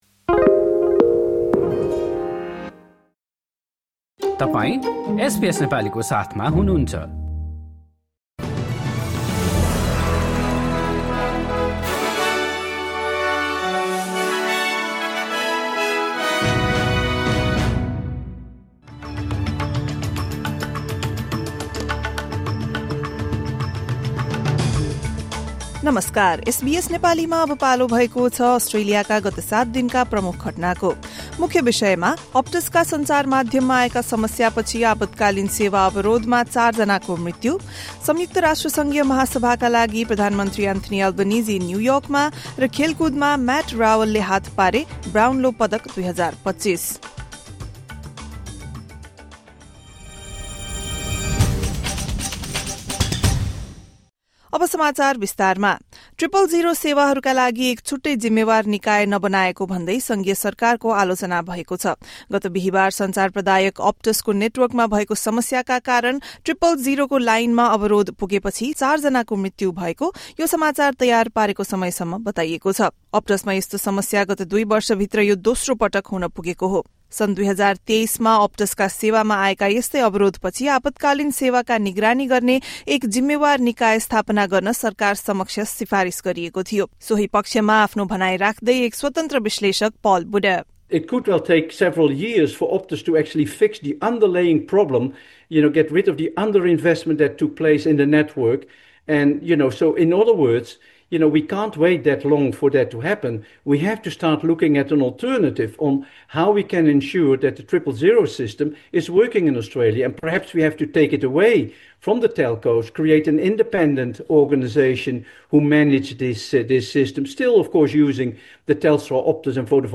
एसबीएस नेपाली अस्ट्रेलियाको हालखबर: गत सात दिनका प्रमुख घटना